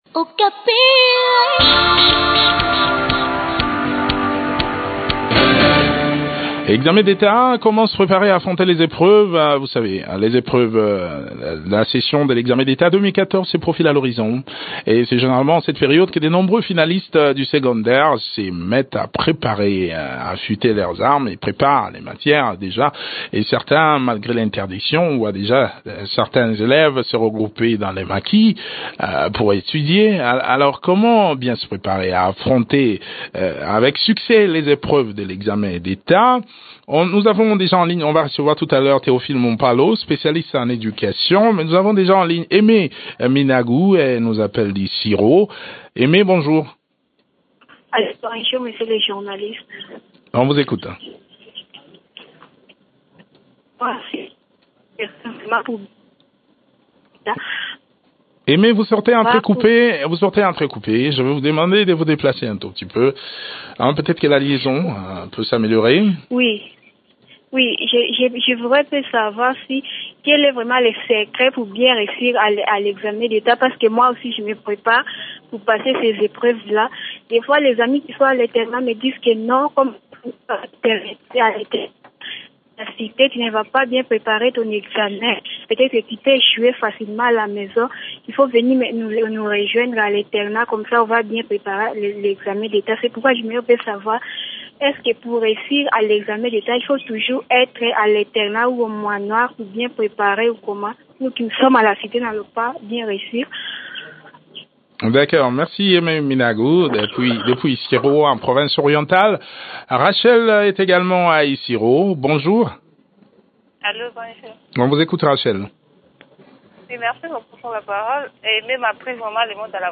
spécialiste en sciences de l’éducation.